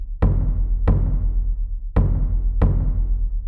Index of /90_sSampleCDs/Best Service ProSamples vol.54 - Techno 138 BPM [AKAI] 1CD/Partition C/UK PROGRESSI
TRASH A   -L.wav